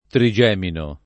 trigemino [ tri J$ mino ]